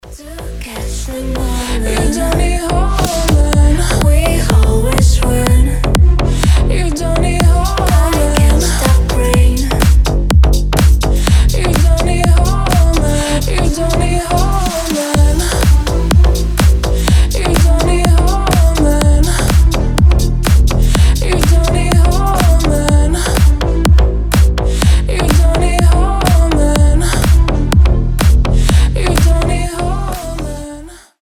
• Качество: 320, Stereo
deep house
женский голос
future house
басы
чувственные